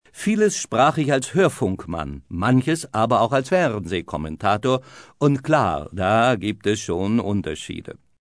Sie sollen streiflichtartig die Wortmächtigkeit und sprachliche Kreativität des Reporters zeigen.